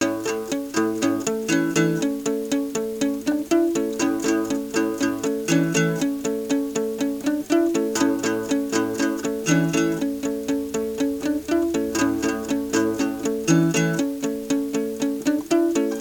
1. Genre: Folk